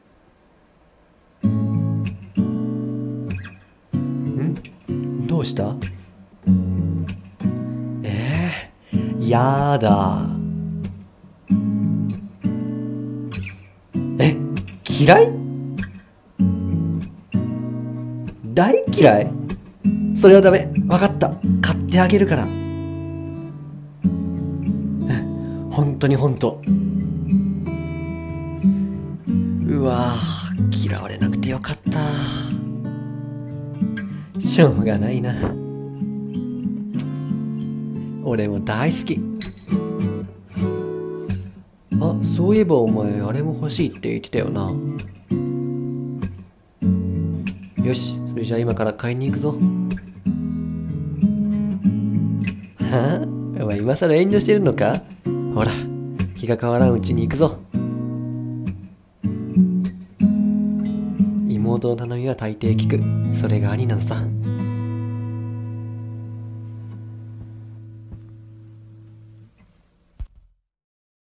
声劇 ／ おにーちゃんこれ買って！コラボ